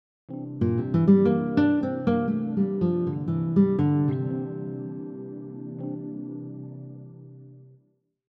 Here, we’re incorporating the Dominant arpeggio with other notes from the C Mixolydian scale.
Dominant 7 arpeggio example 3
You can also think of the beginning as a C 9 chord arpeggio which extends past the basic 7th chord structure.
Dominant-7-arpeggio-example-3.mp3